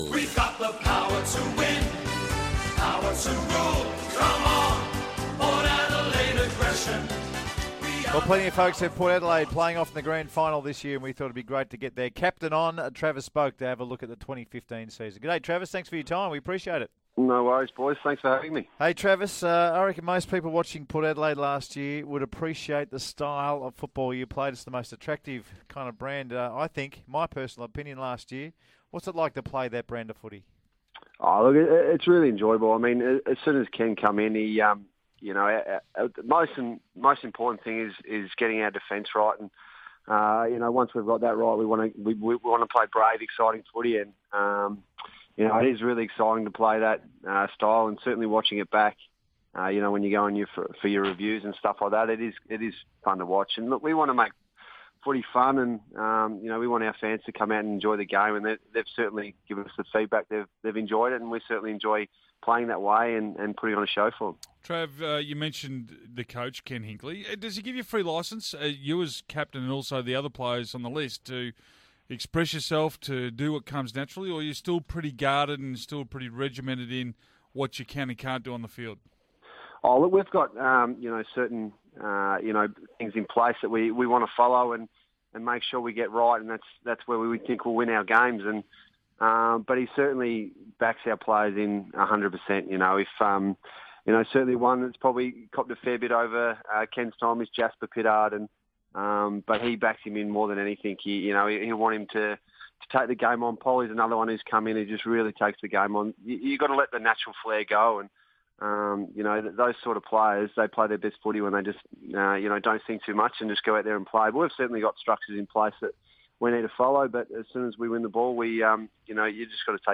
Travis Boak SEN interview - Monday 23rd March, 2015
Travis Boak speaks to the guys on SEN